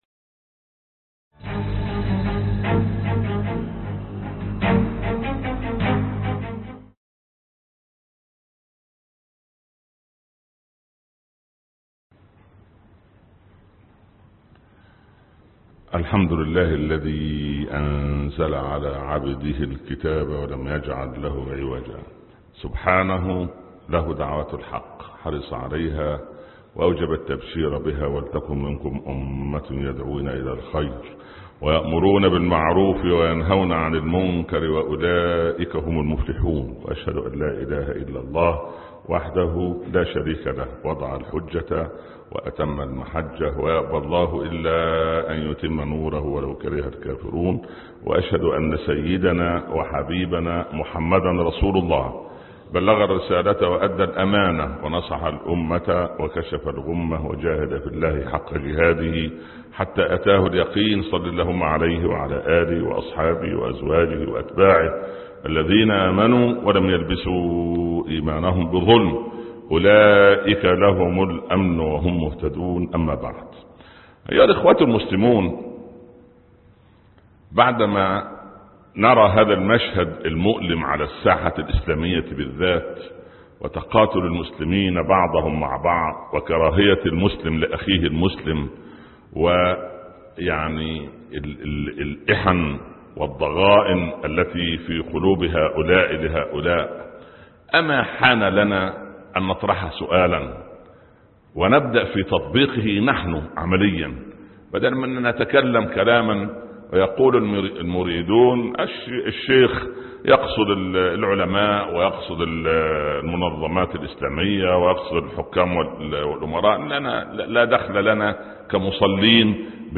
أمة واحدة (12/8/2016) خطب الجمعة - الشيخ عمر بن عبدالكافي